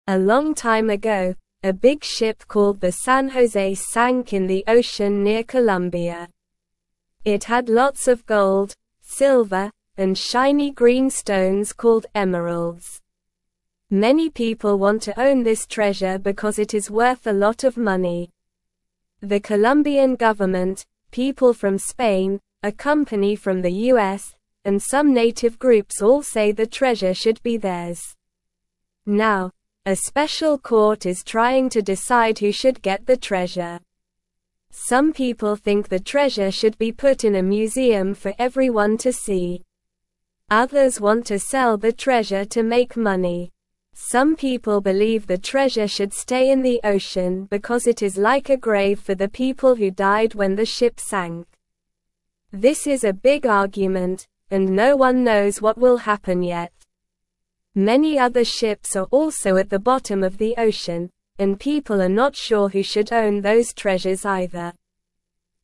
Slow
English-Newsroom-Beginner-SLOW-Reading-The-Big-Ship-Treasure-Who-Should-Own-It.mp3